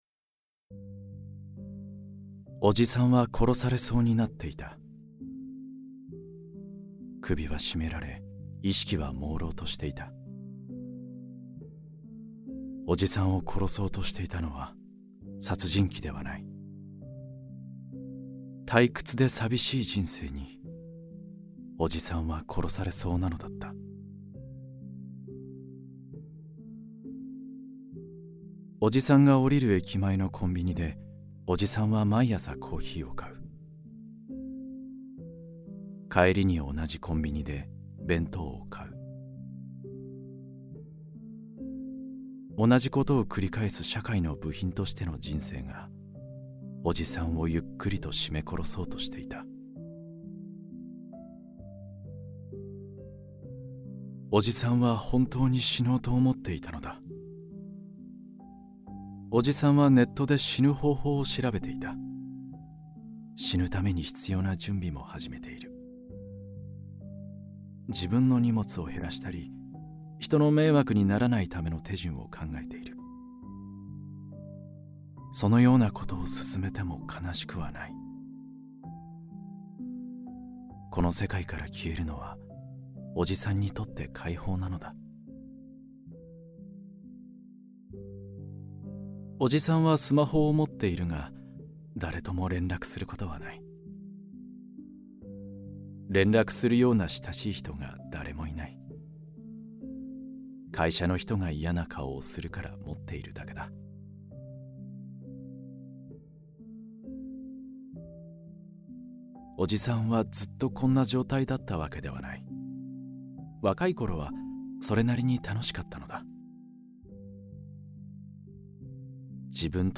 [オーディオブック] おじさんが伝説になるまで